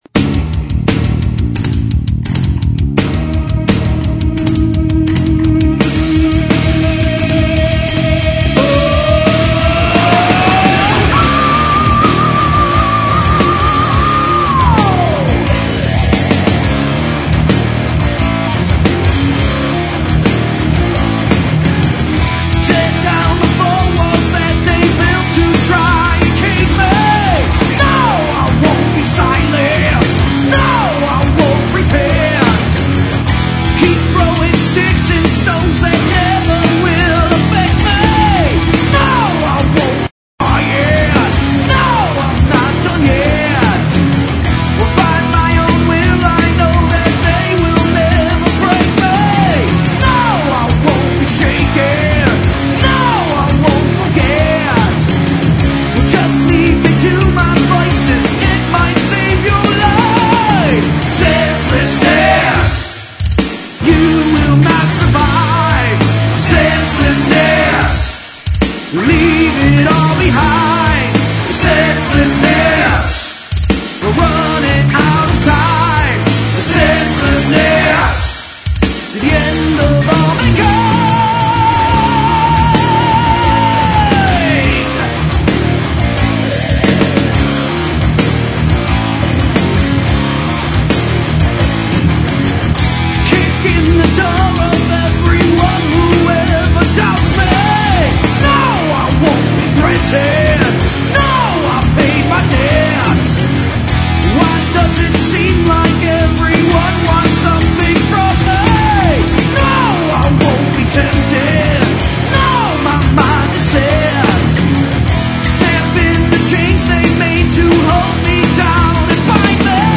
Crunchy, edgy, rock RNRA